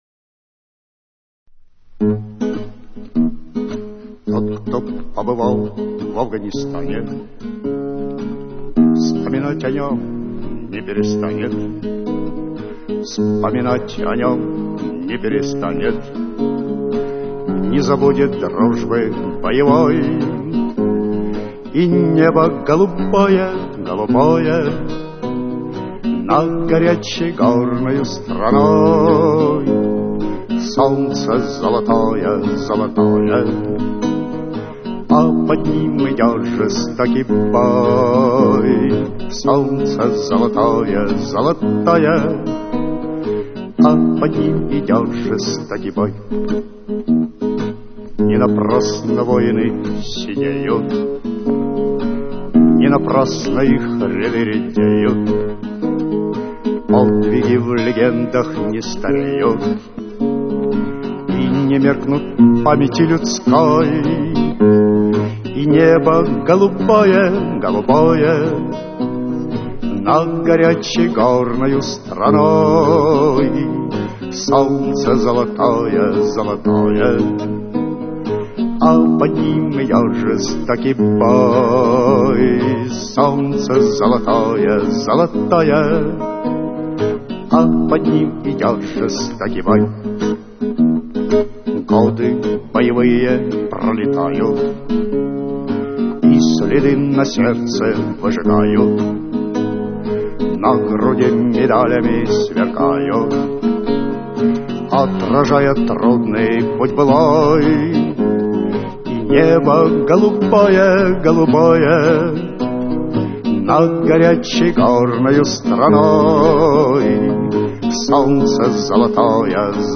Архив mp3 / Слово и музыка / Военная песня /